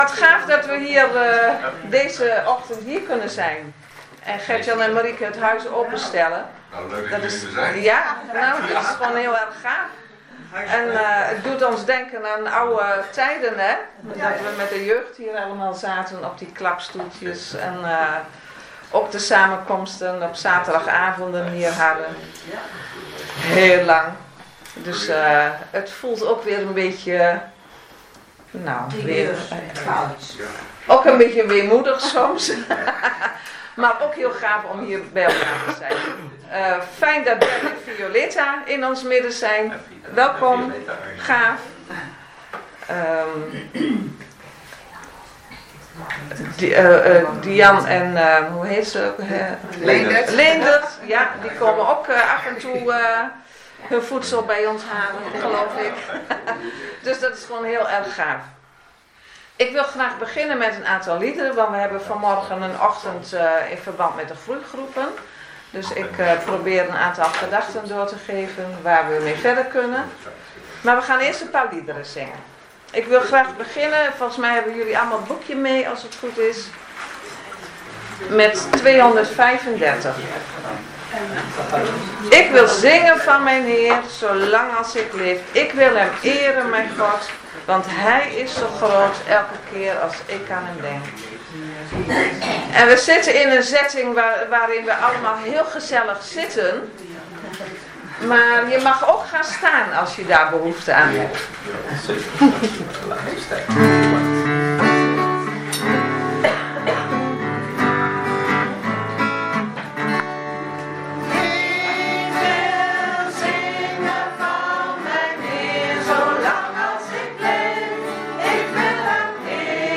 15 februari 2026 dienst - Volle Evangelie Gemeente Enschede